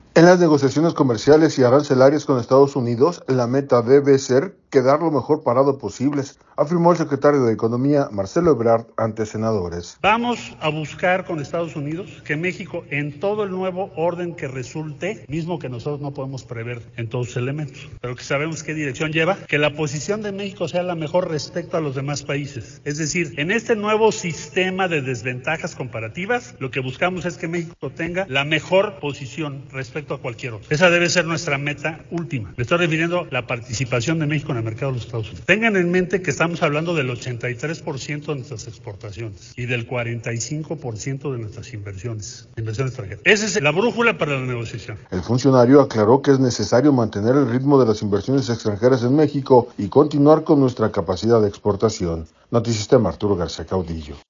En las negociaciones comerciales y arancelarias con Estados Unidos la meta debe ser quedar lo mejor parado posibles, afirmó el secretario de Economía, Marcelo Ebrard ante senadores.